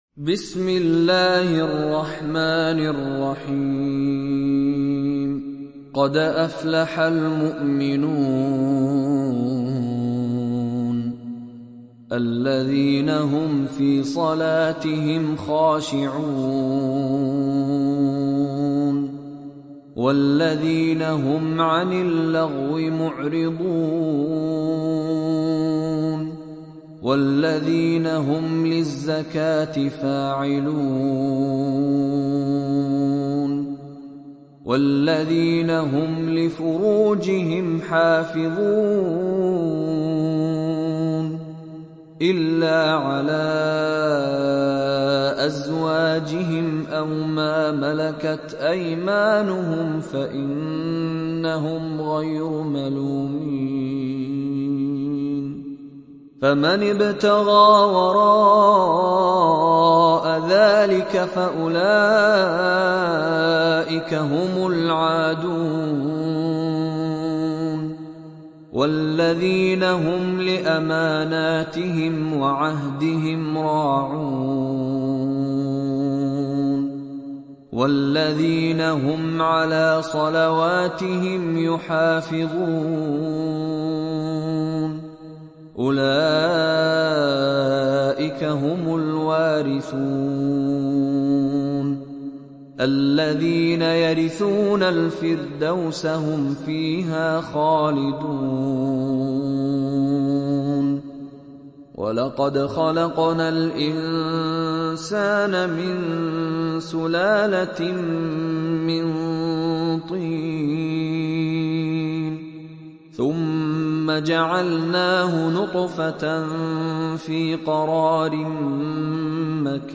Sourate Al Muminun mp3 Télécharger Mishary Rashid Alafasy (Riwayat Hafs)